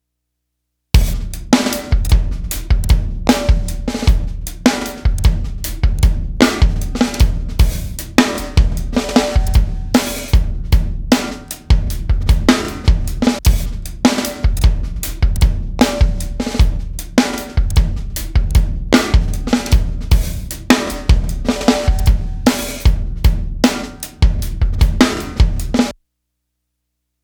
As an example I hit the REC button & tracked two loose grooves with some imperfections pretty far off the grid. I then ran them thru an extreme rough & dirty uncleaned Beat Detective pass. You can hear how the bounce is stripped & creates a lifeless groove.
Loose Groove One Over Quantized.wav
Also, there’s all those nasty artifacts…
Nice drumming + great sounding kit!
Loose-Groove-One-Over-Quantized.wav